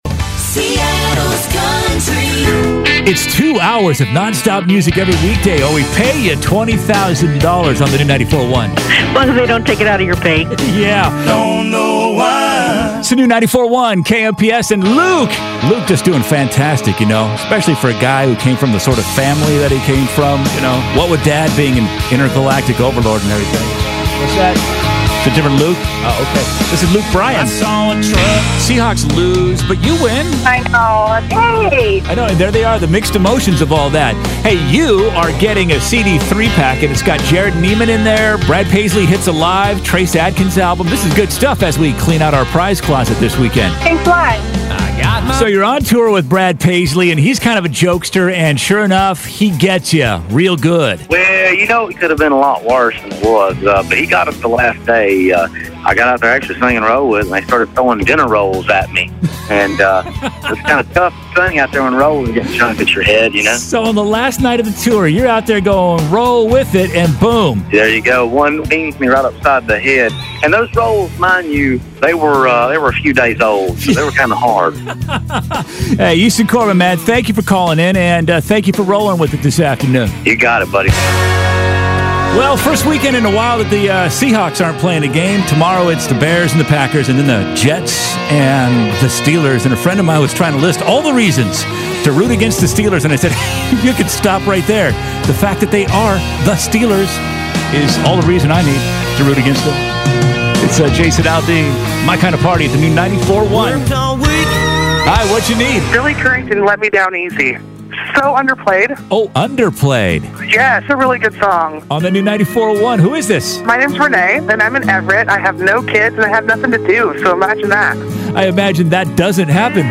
WEEKENDS AIRCHECK